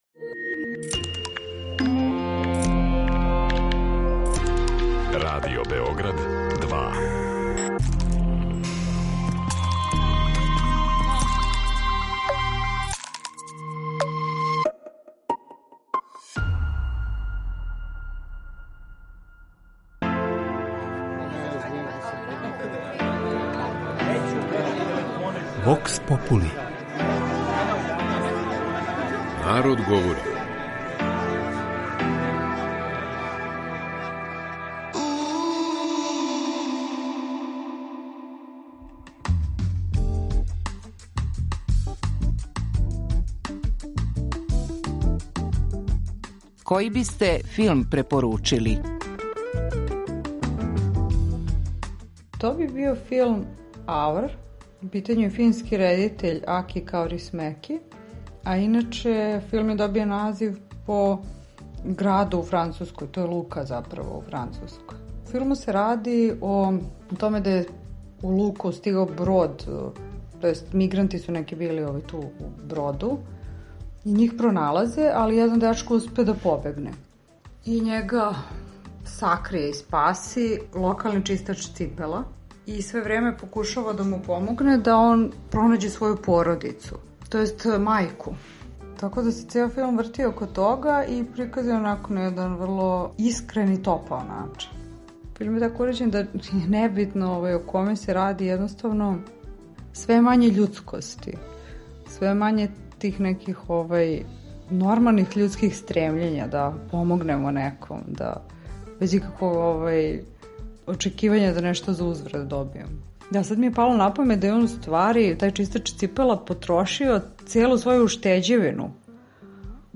У данашњој емисији Вокс попули чућемо филмске препоруке наших слушалаца које нам могу помоћи кад следећи пут бирамо филм.